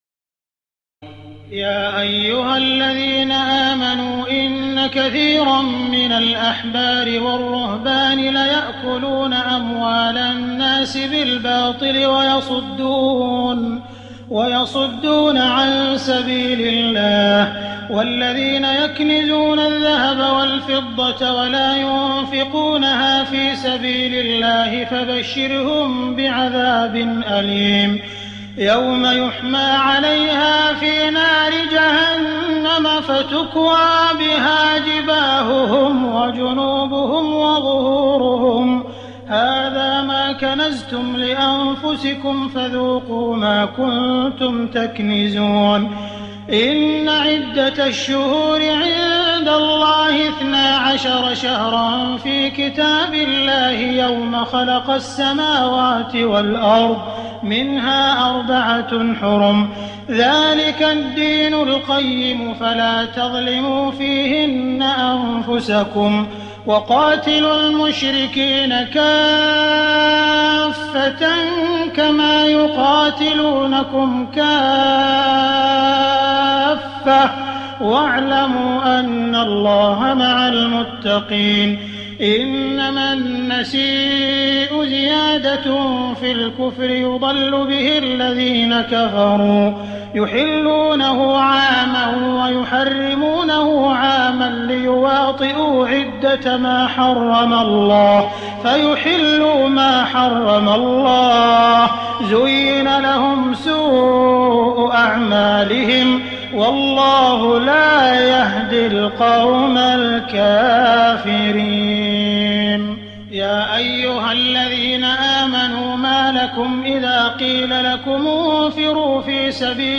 تراويح الليلة التاسعة رمضان 1419هـ من سورة التوبة (34-93) Taraweeh 9 st night Ramadan 1419H from Surah At-Tawba > تراويح الحرم المكي عام 1419 🕋 > التراويح - تلاوات الحرمين